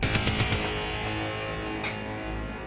samurai-cop-theatrical-trailer-remastered-version.mp3